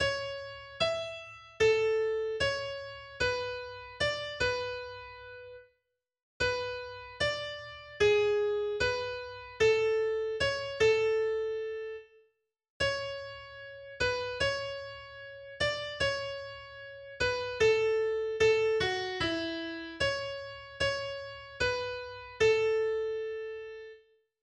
310   "Fast Train {Diskant}" (A-Dur, eigene) .pdf .capx .mid